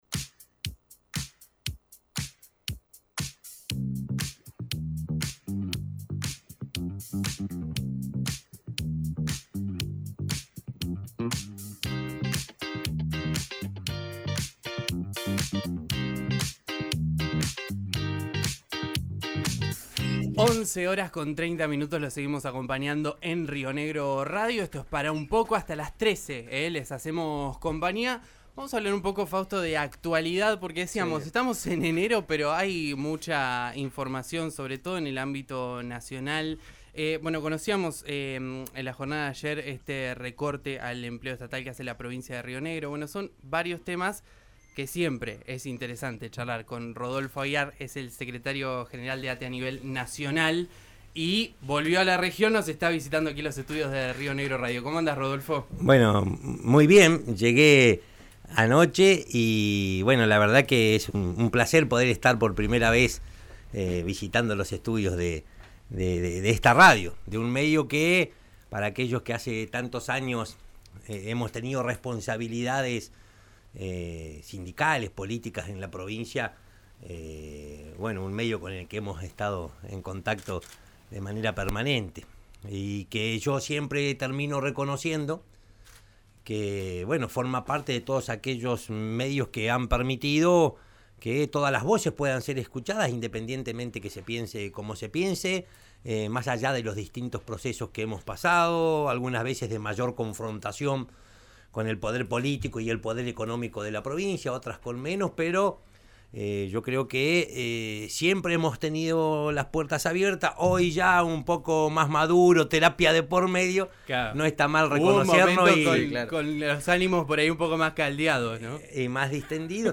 Rodolfo Aguiar, secretario general de ATE nacional, visitó los estudios de RÍO NEGRO RADIO y fijó postura ante la no renovación de contratos a estatales en Río Negro.